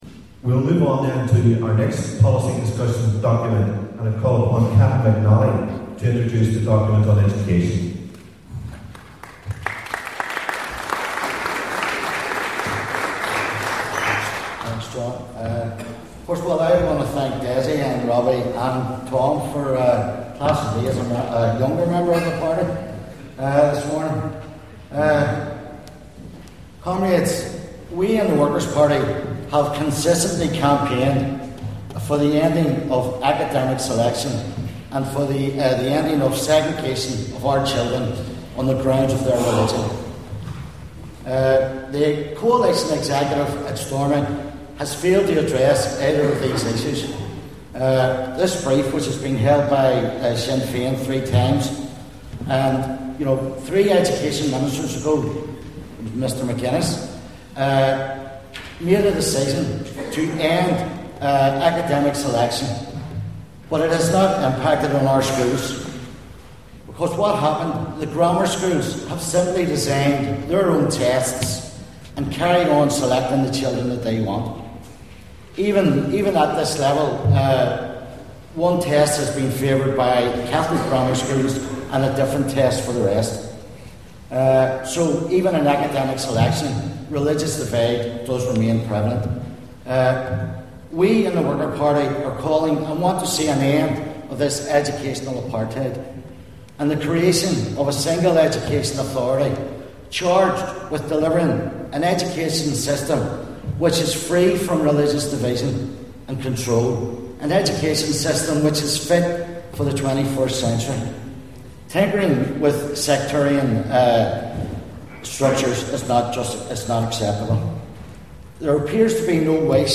Conference session